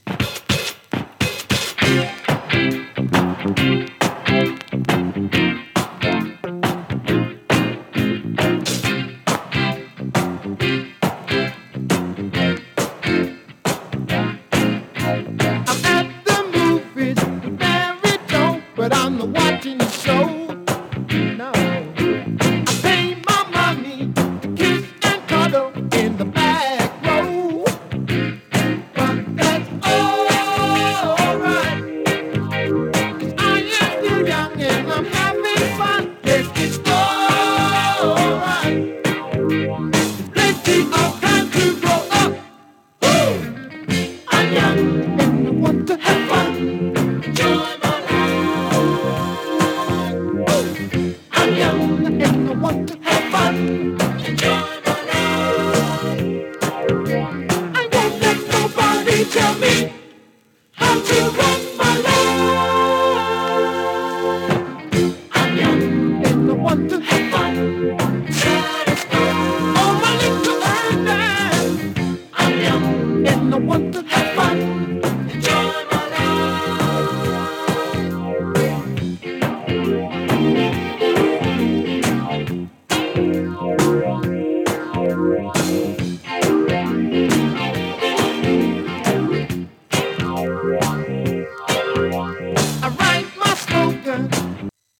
[2track 12inch]＊稀に軽いパチ・ノイズ。